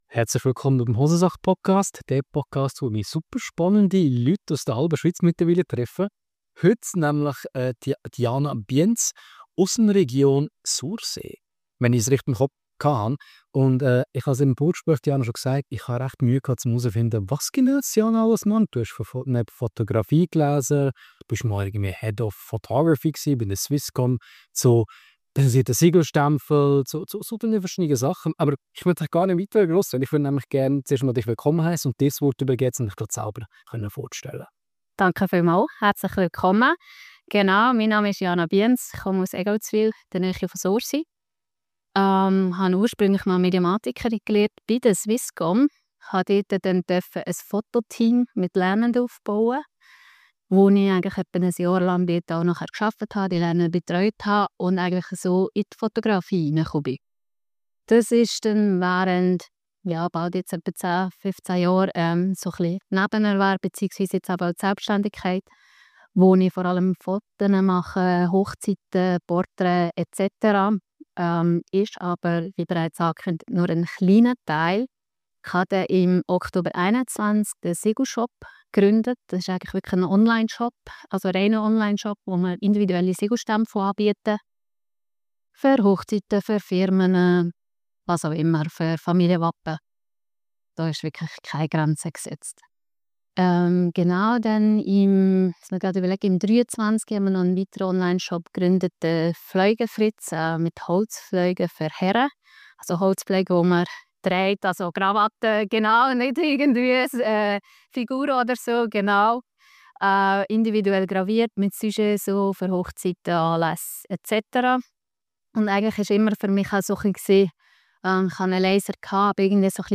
Und natürlich auch über Flexibilität, Freiheit und die Realität von Selbstständigkeit mit Kind. Ein inspirierendes Gespräch mit einer kreativen Unternehmerin, die mit viel Ruhe und Fokus ihren eigenen Weg geht.